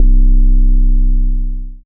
DDW6 808 6.wav